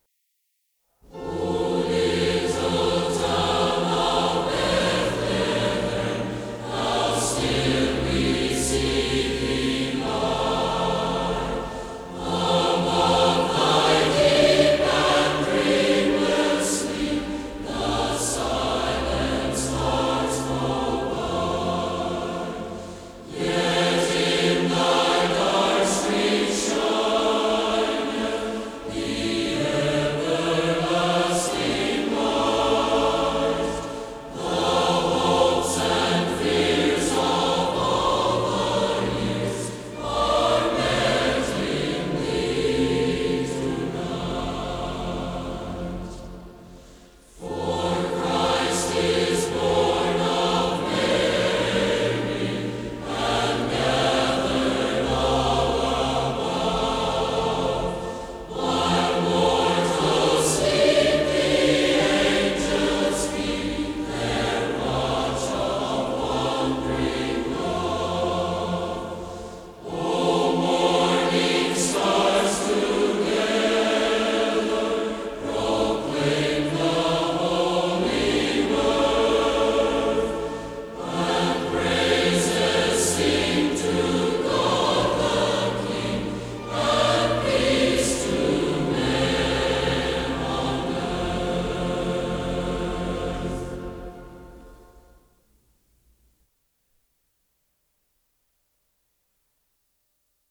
Choir
Organ